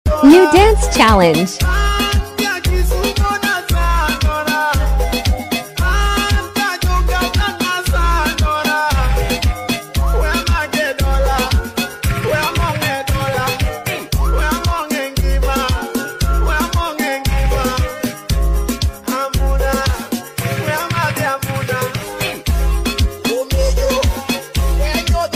luo song